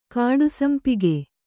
♪ kāḍu sampige